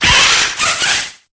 Cri_0850_EB.ogg